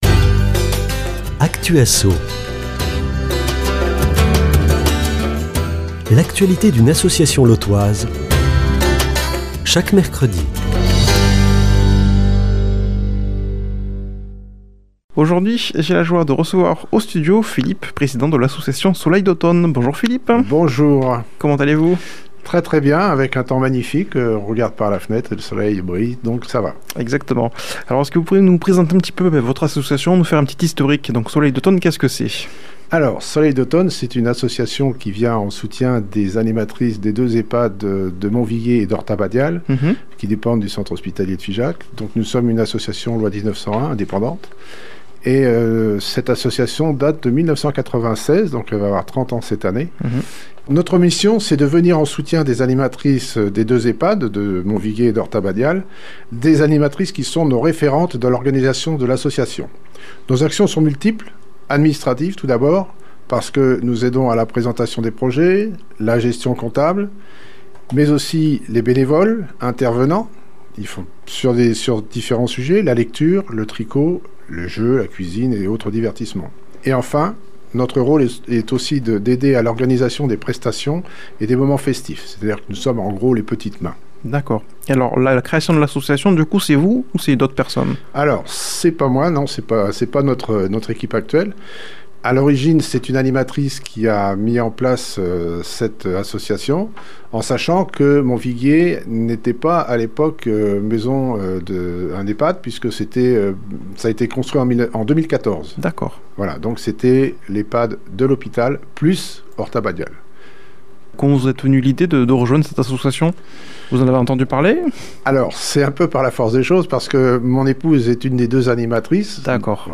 Actu Asso